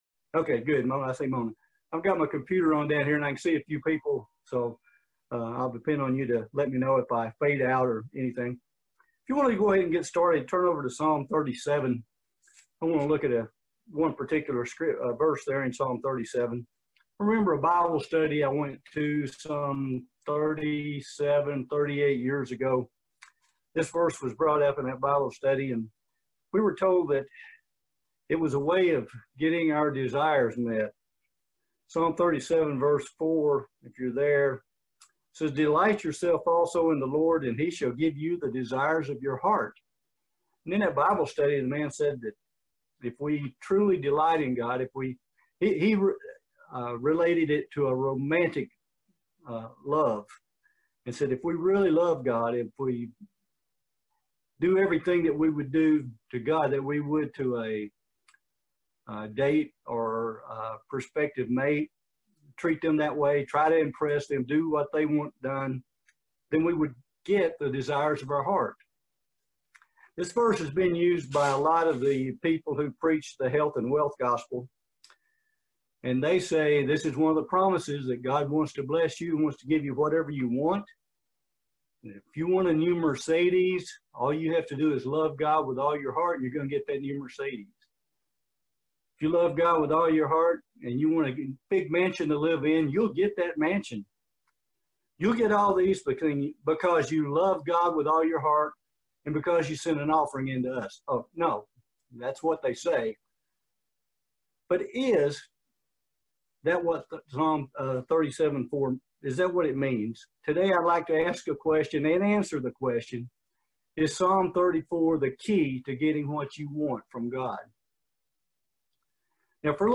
Excellent video split sermon on not getting distracted from our doing the work of God. The world offers us lots of distractions to draw us away from God , and we have to keep our faith in perspective.
Given in Lexington, KY